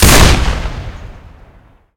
deagleShoot.ogg